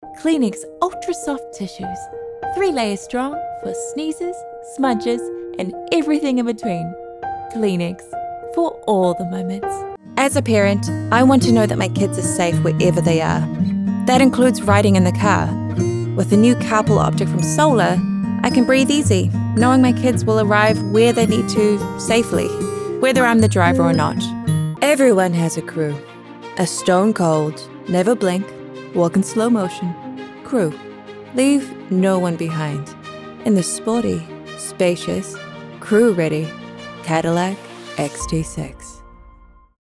English | New Zealand
commercial